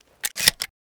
Spas-12 Reanimation / gamedata / sounds / weapons / spas / load.ogg